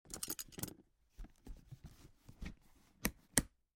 Звуки стетоскопа
Звук, коли лікар дістає стетоскоп із свого чемоданчика